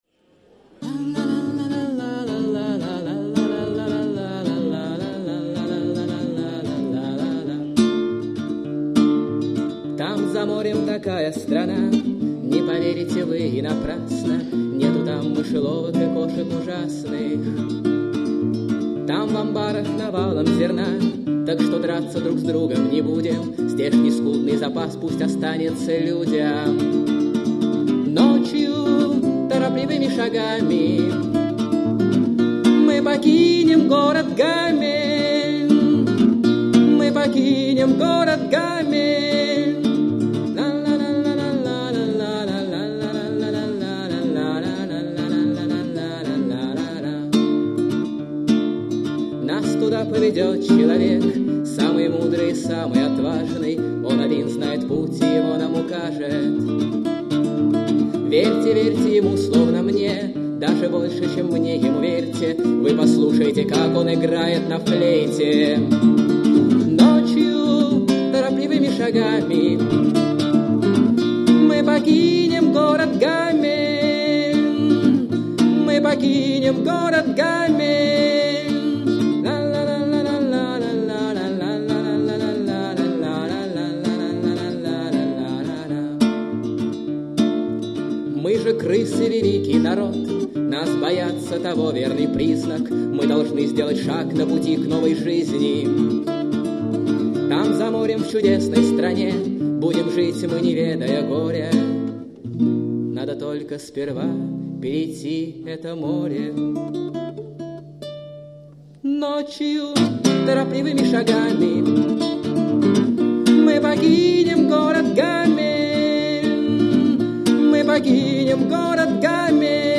А вот здесь вот я нашёл хорошую, но печальную песенку: